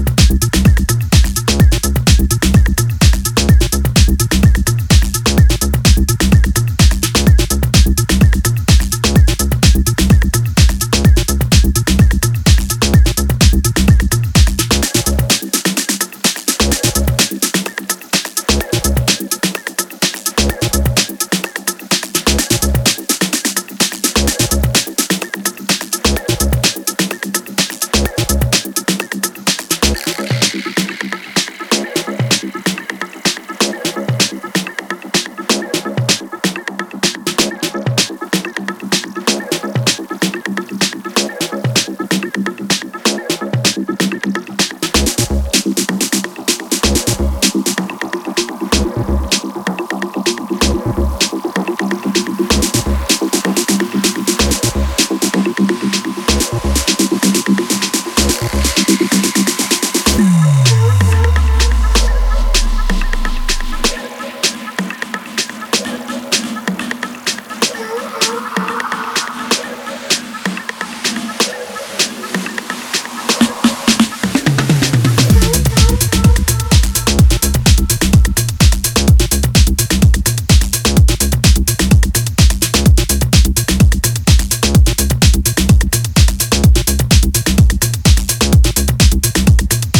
ジャンル(スタイル) DEEP HOUSE / TECH HOUSE / MINIMAL